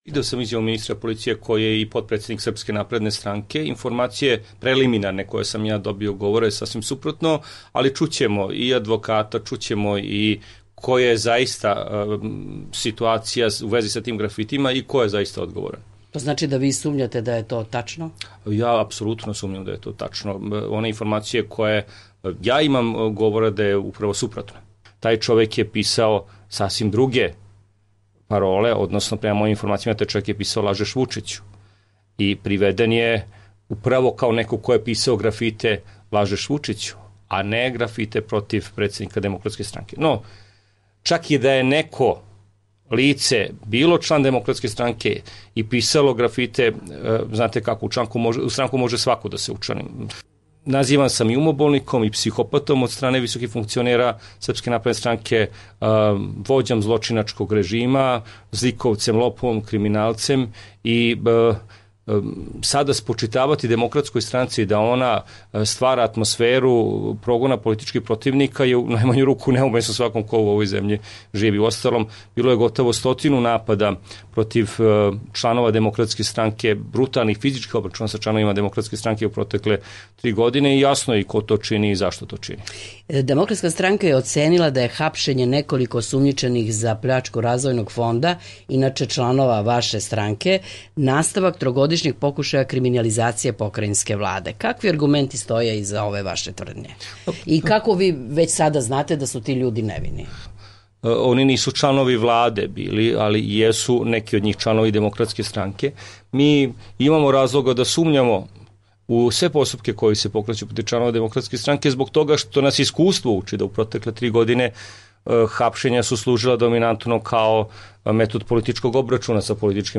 Intervju sa Bojanom Pajtićem